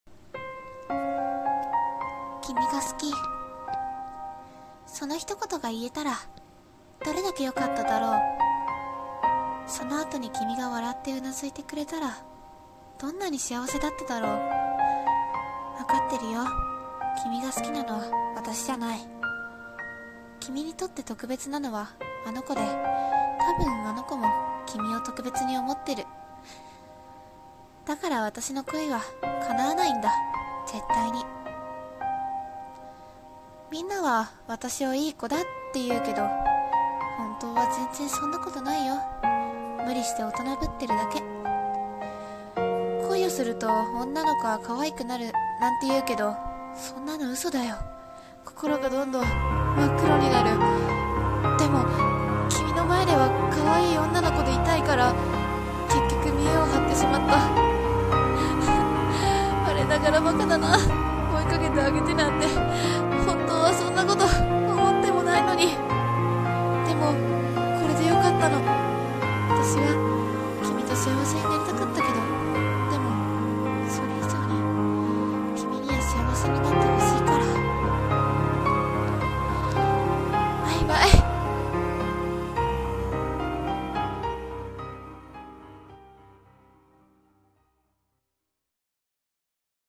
朗読声劇「叶わない